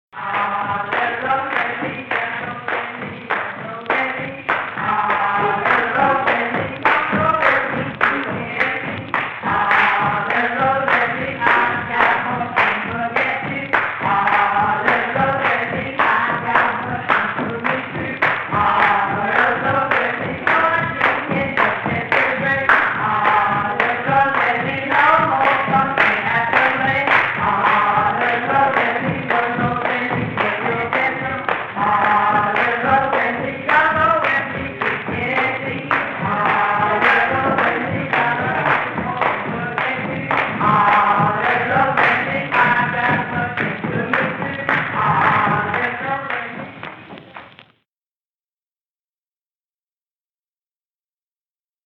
Field recordings paired with these images were recorded in rural Mississippi by John and Alan Lomax between 1934 and 1942.